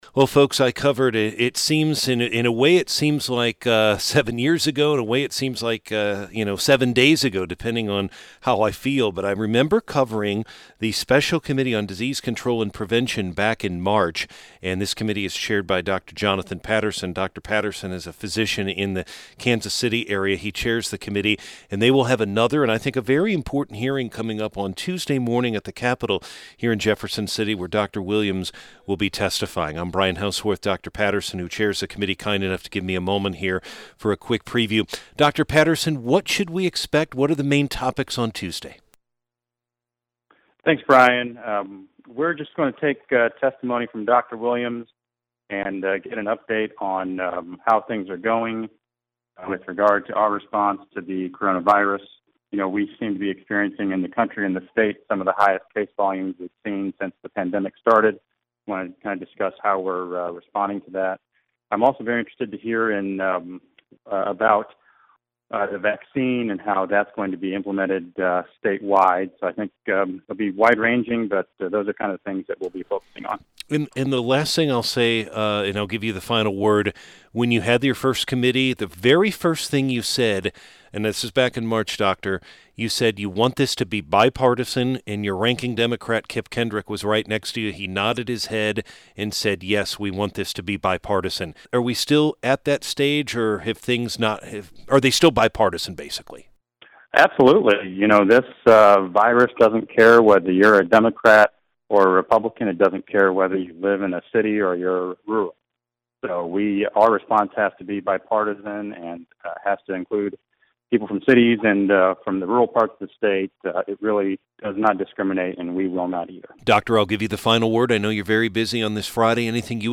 bh-pattersoninterview.mp3